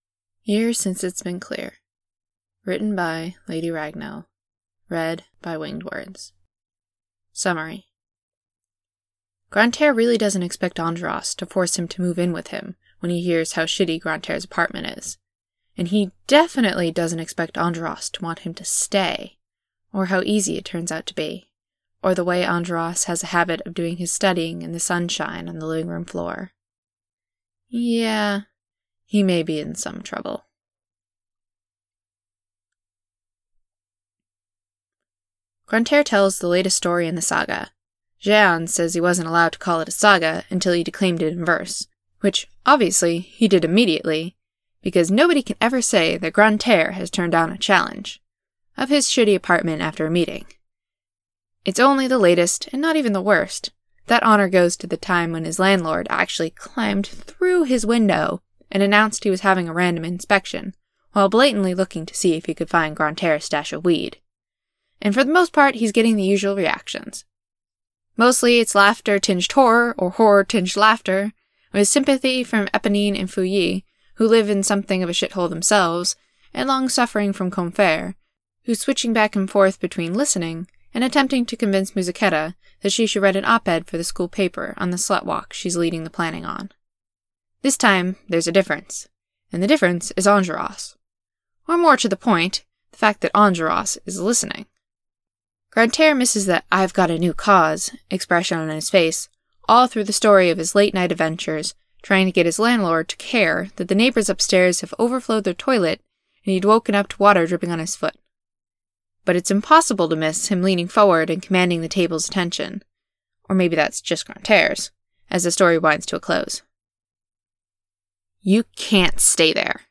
I actually got a bit of it done, but had to start over because everything about my podficcing set-up had changed too much.
I couldn't remember my own Enjolras voice when I started recording, so I used yours.